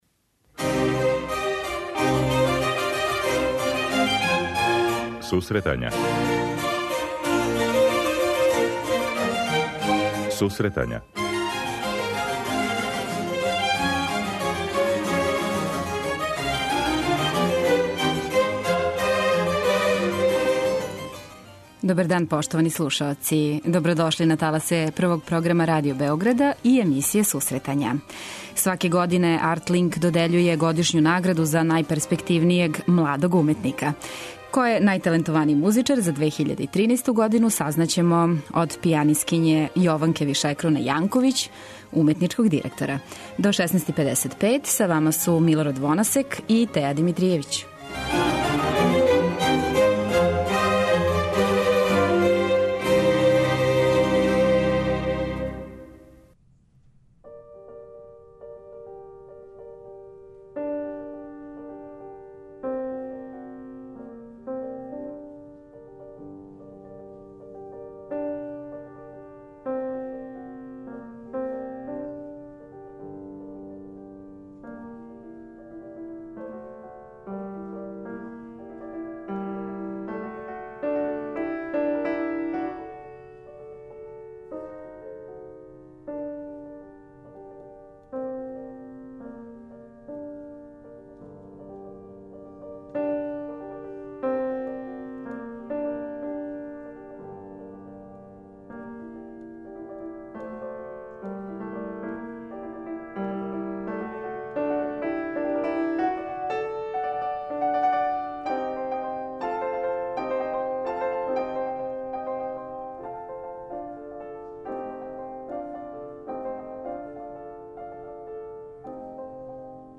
преузми : 25.96 MB Сусретања Autor: Музичка редакција Емисија за оне који воле уметничку музику.